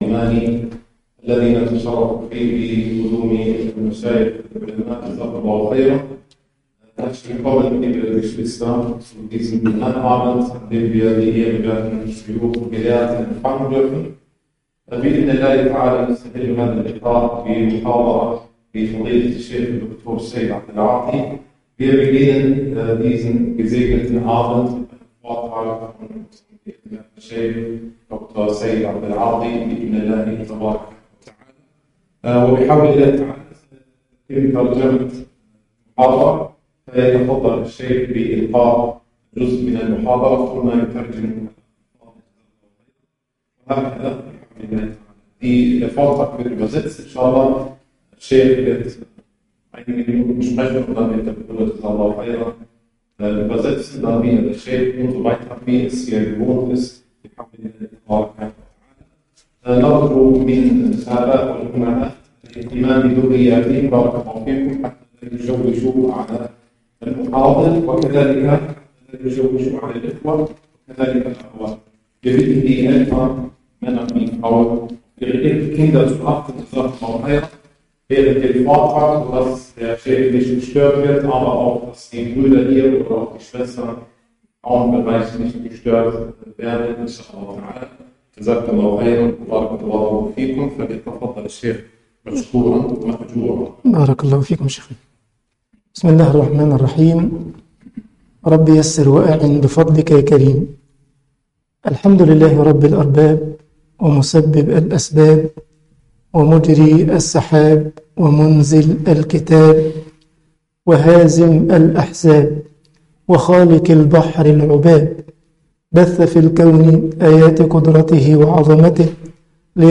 Umsiah imaniah - Takreem Allah li-Adam - Masjid Bilal Frankfurt.mp3